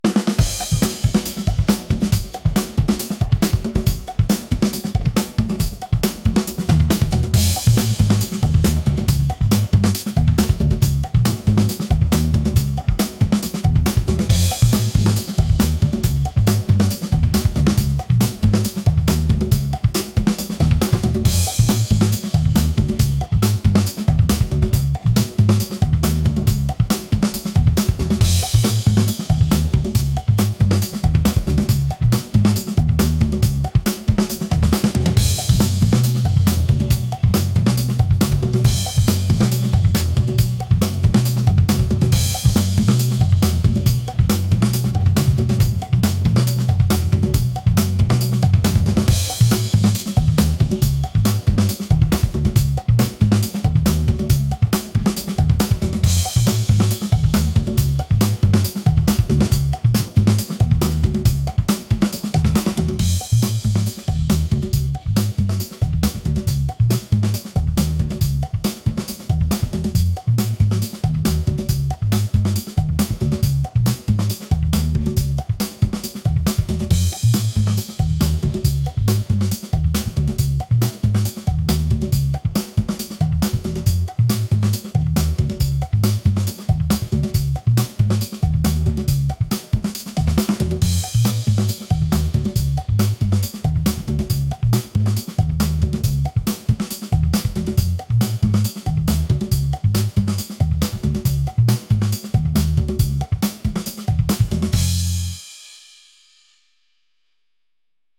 energetic | rock | groovy